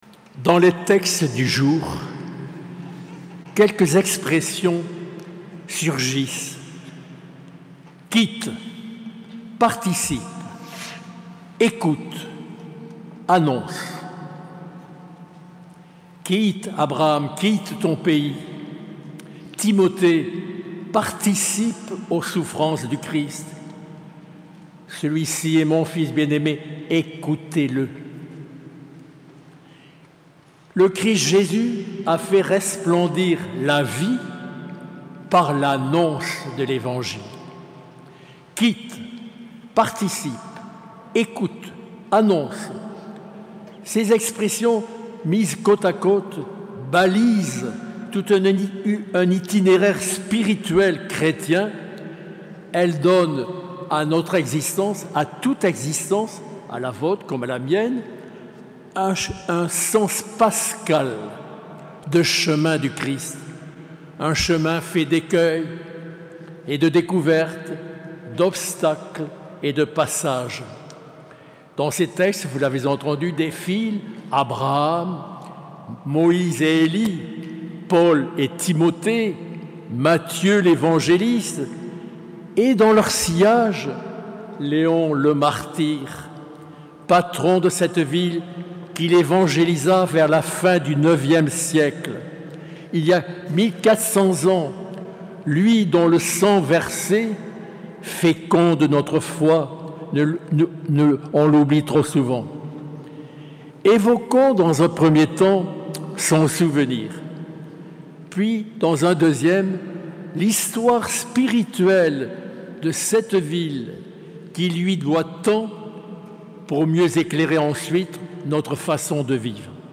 Homélie enregistrée le dimanche 5 mars 2023 en la cathédrale Sainte-Marie de Bayonne.